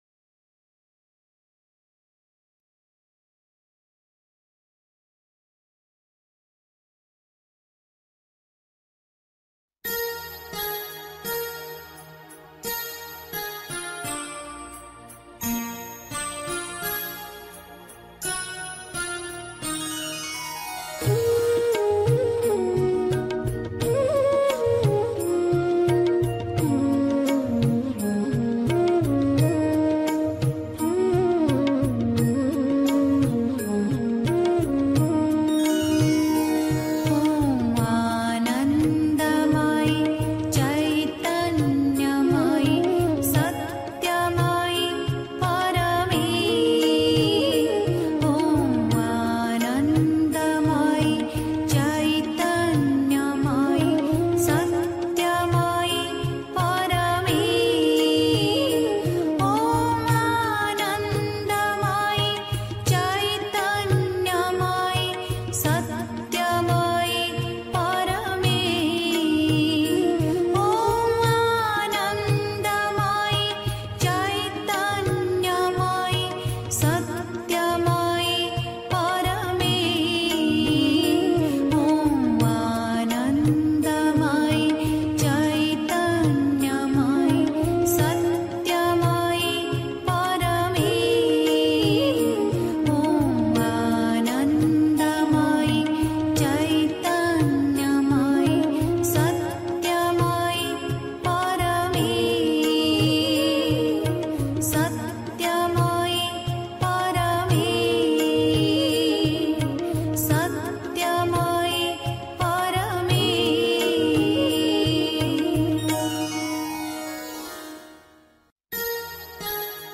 1. Einstimmung mit Musik. 2. Bewahre dich vor Verzweiflung (Die Mutter, The Sunlit Path) 3. Zwölf Minuten Stille.